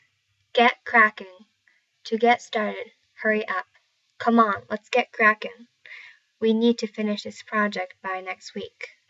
get cracking とは、急いで取りかかるという意味ですが、特に仕事について使われる表現です。 英語ネイティブによる発音は下記のリンクをクリックしてください。